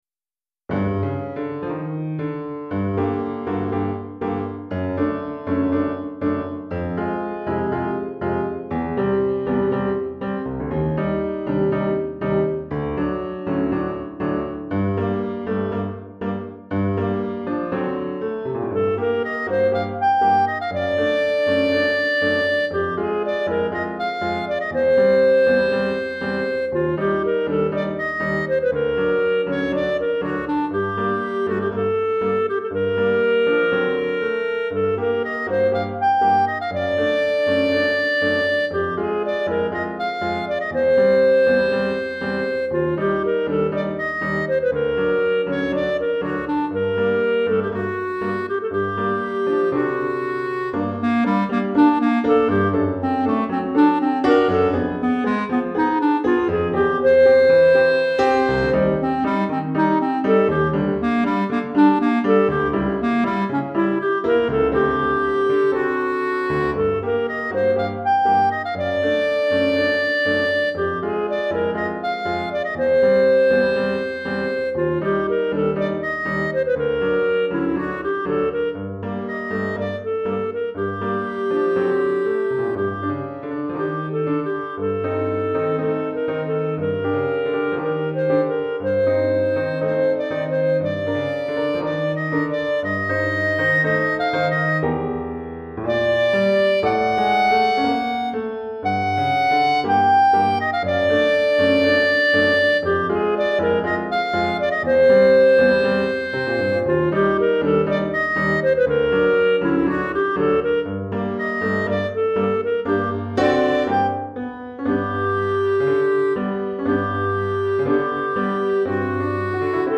Clarinette et Piano